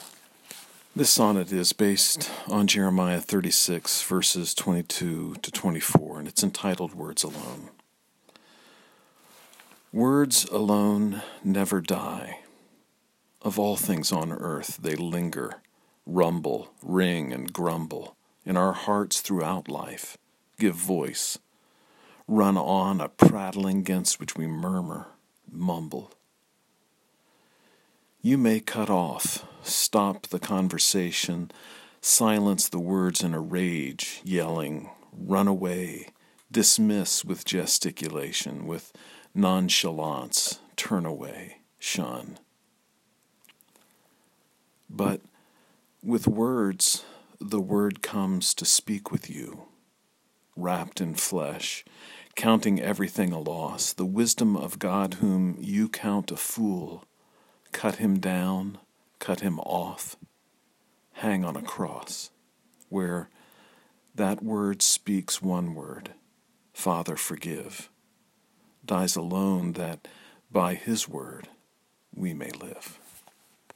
If it’s helpful, you may listen to me read the sonnet via the player below.